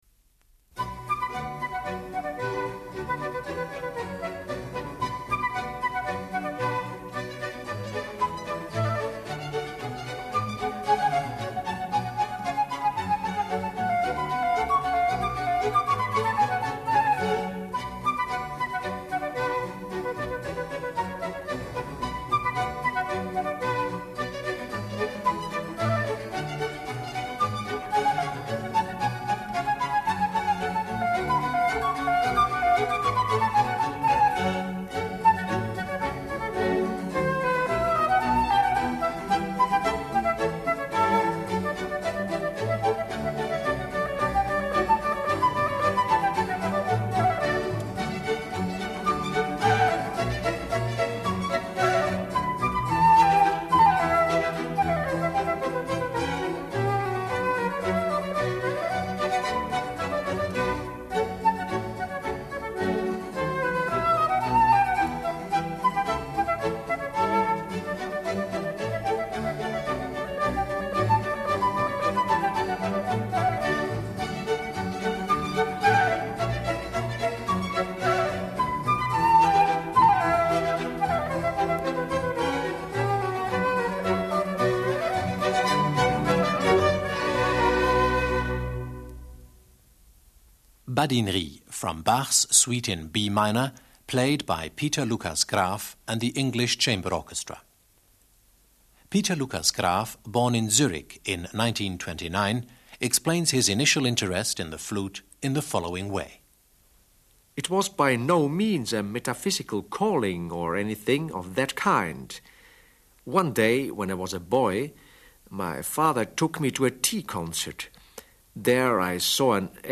From Suite no. 2 in B minor.Peter-Lukas Graf, flute and conductor.
Peter-Lukas Graf speaks (I). 3.
harpischord.
bassoon. 4.
Leading Swiss Artists of the Concert Stage. Peter-Lukas Graf (flute).
for solo flute